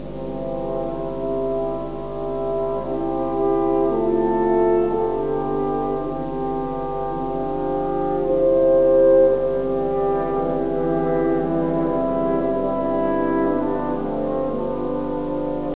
Beginn der Ouvertüre (174 KB)